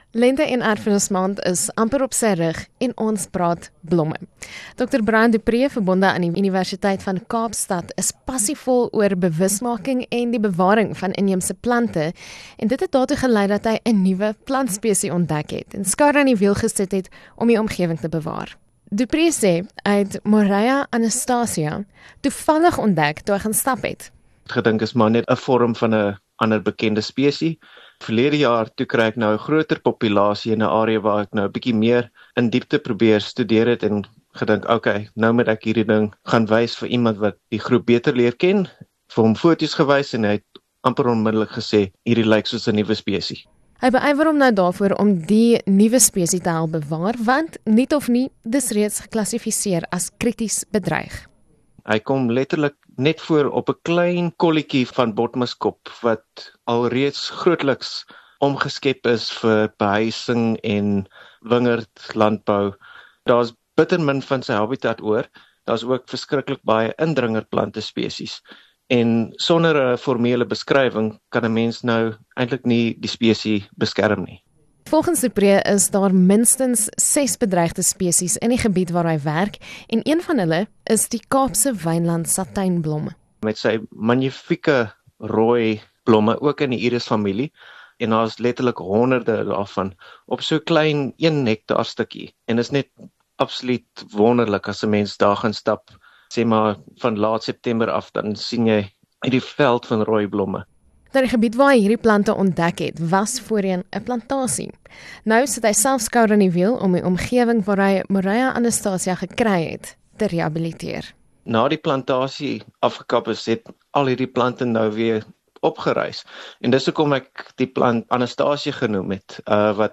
Hear the latest news interviews from the Jacaranda FM News team. The Jacaranda FM News covers local and international news of the day, providing the latest developments online and on-air.